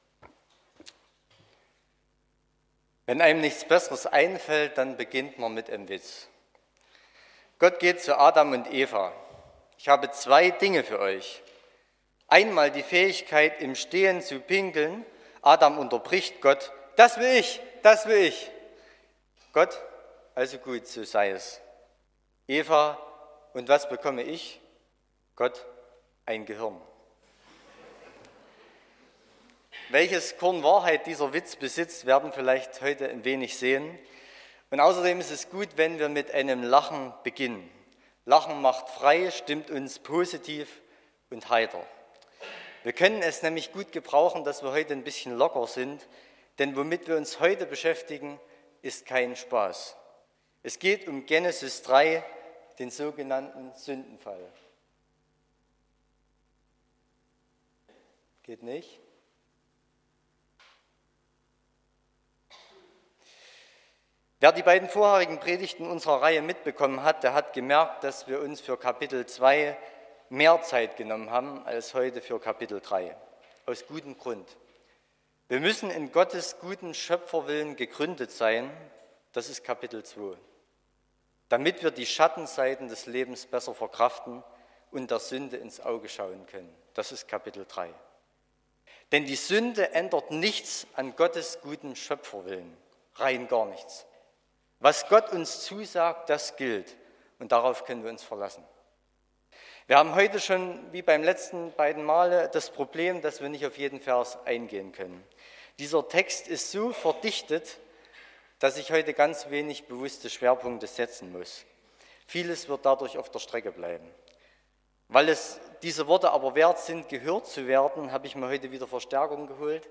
07.04.2024 – Landeskirchliche Gemeinschaft
Predigt (Audio): 2024-04-07_Themenreihe__Geschaffen_zum_Leben__Teil_3.mp3 (39,6 MB)